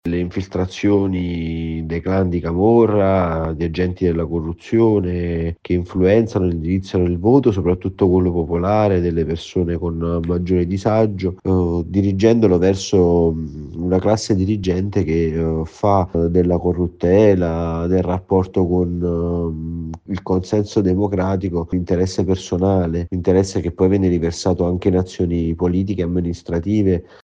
Guerre e crisi climatica sempre più legate nelle nuove rotte migratorie. Ascoltiamo il servizio